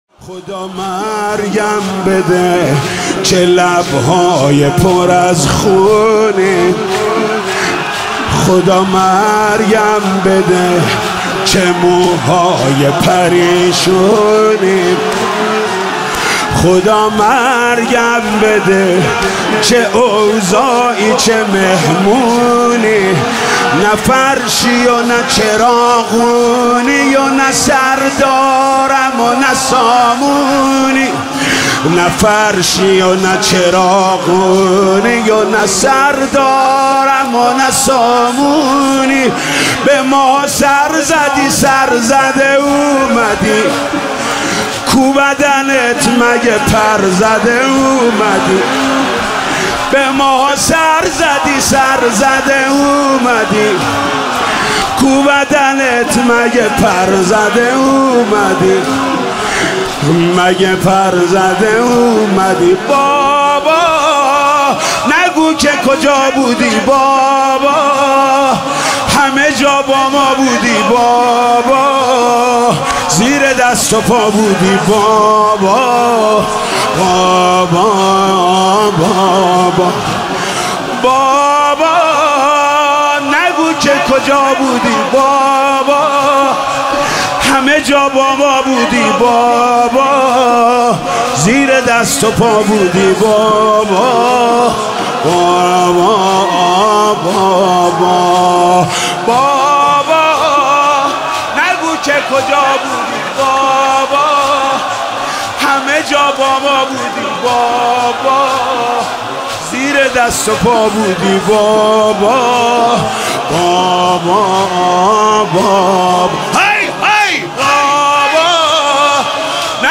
لیلة 3 محرم - 1441 هجري